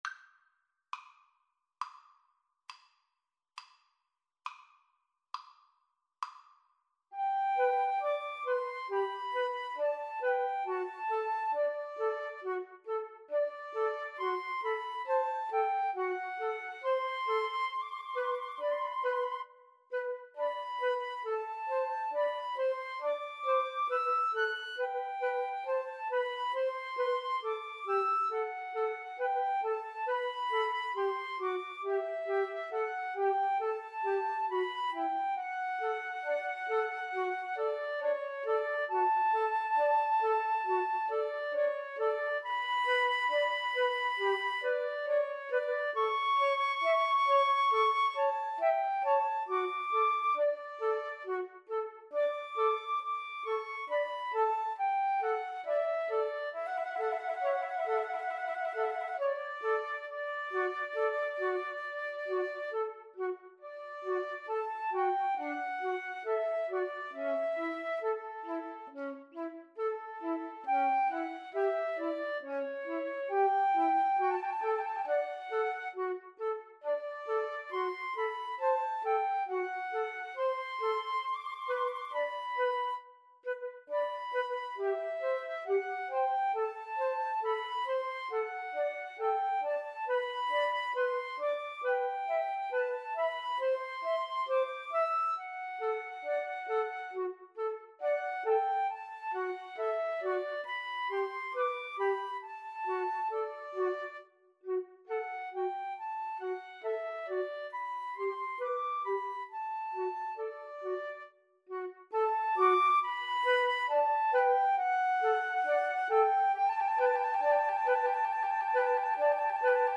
= 34 Grave
Classical (View more Classical 2-Flutes-Clarinet Music)